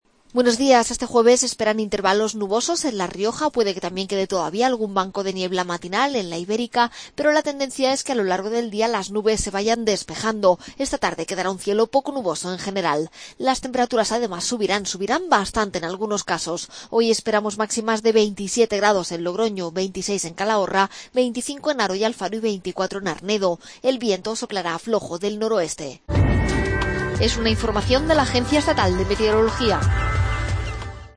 AUDIO: Pronóstico. Agencia Estatal de Meteorología.